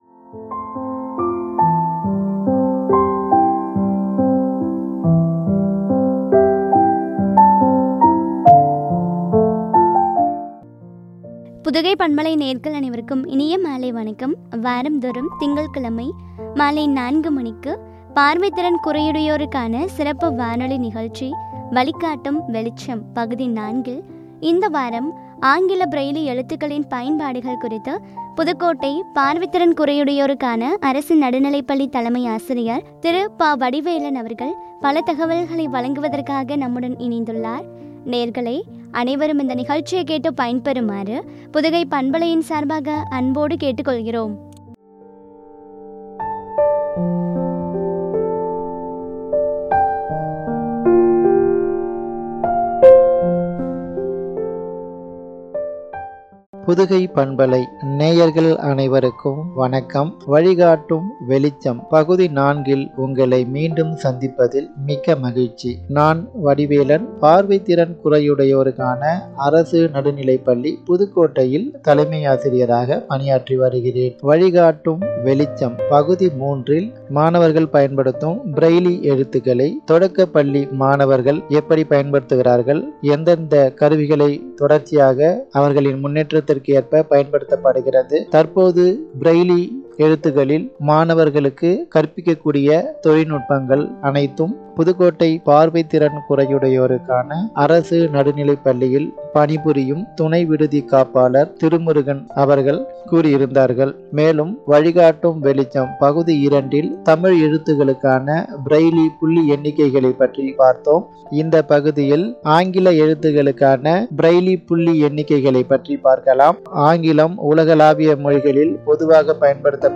பார்வை திறன் குறையுடையோருக்கான சிறப்பு வானொலி நிகழ்ச்சி
” ஆங்கில பிரெய்லி எழுத்துக்களின் பயன்பாடு” குறித்து வழங்கிய உரையாடல்.